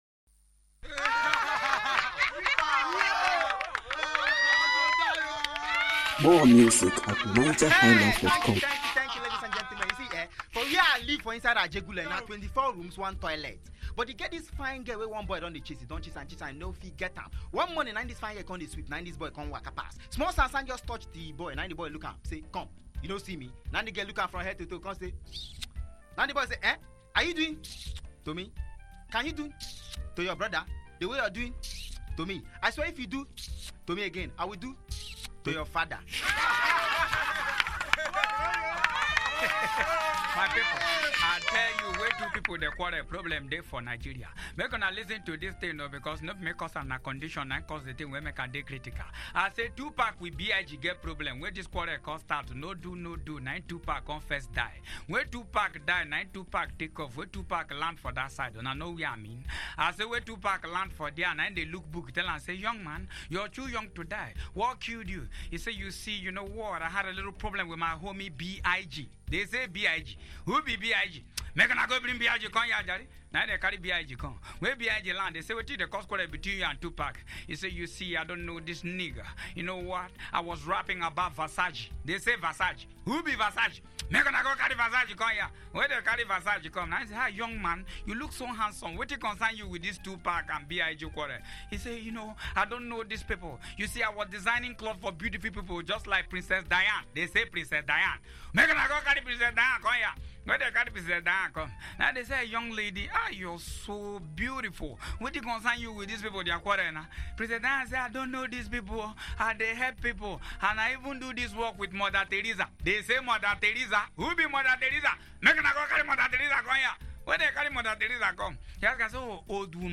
Nigerian Reggae Music